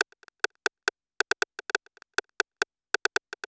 RIM DELAYE-R.wav